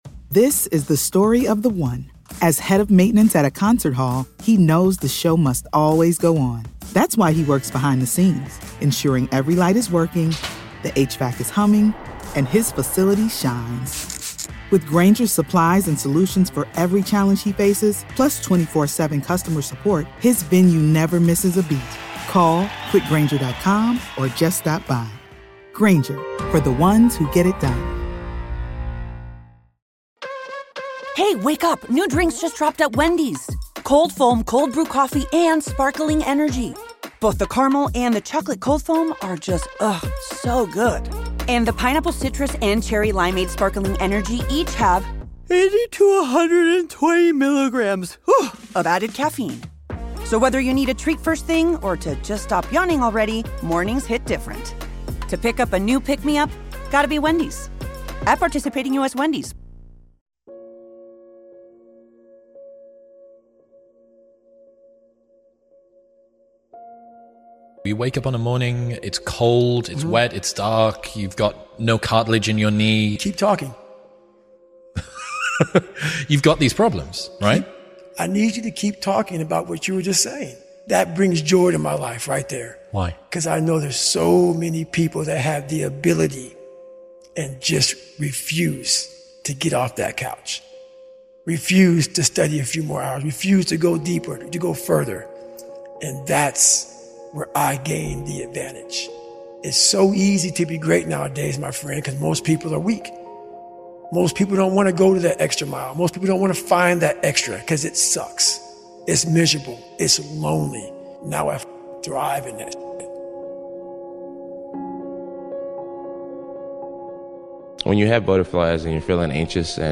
50 Powerful Motivational Speeches